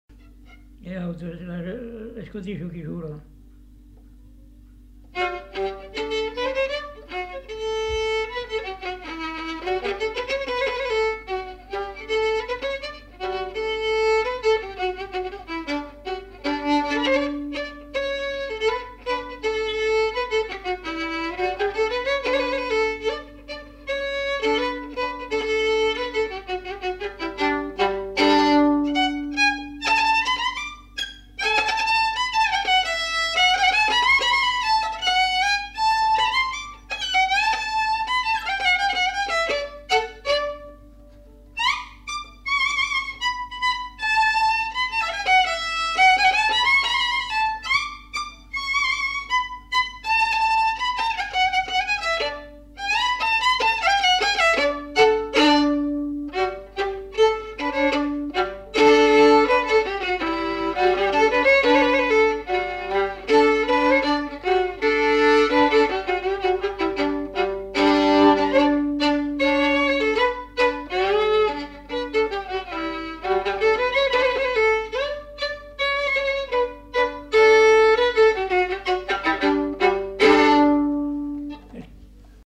Aire culturelle : Lomagne
Genre : morceau instrumental
Instrument de musique : violon
Danse : scottish